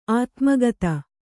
♪ ātmagata